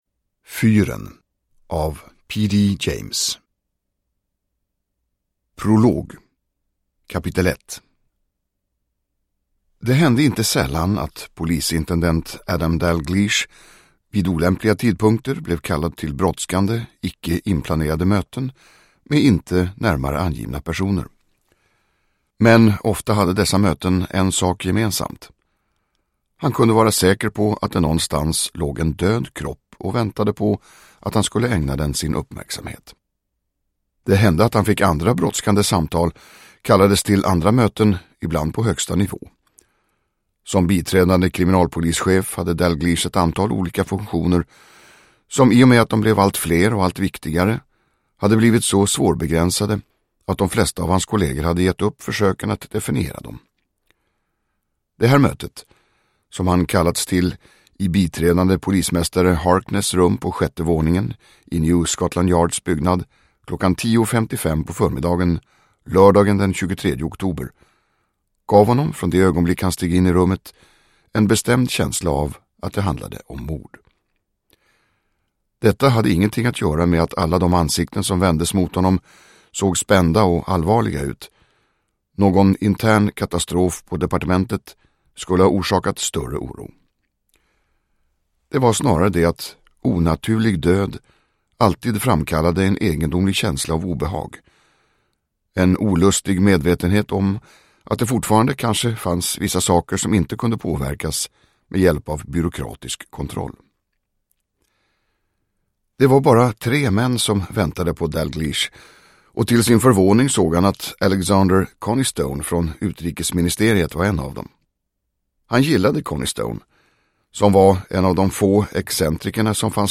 Fyren – Ljudbok – Laddas ner
Uppläsare: Tomas Bolme